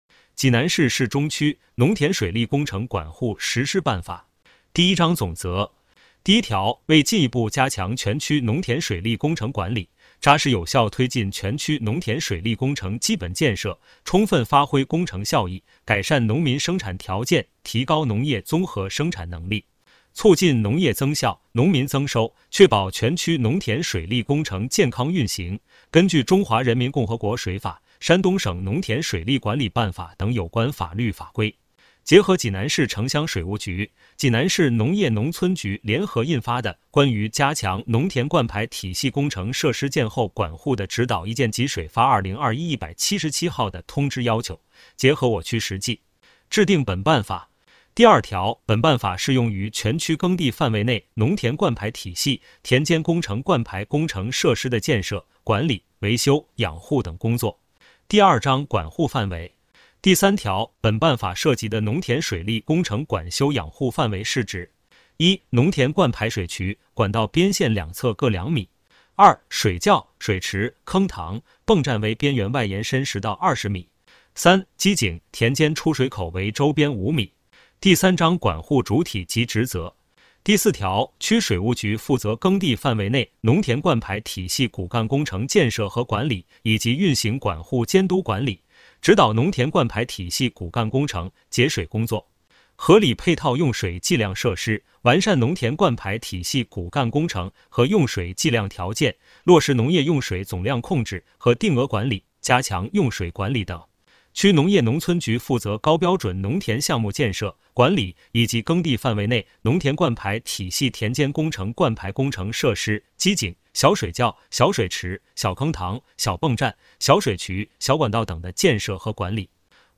区水务局 - 有声朗读 - 音频解读：济南市市中区农田水利工程管护实施办法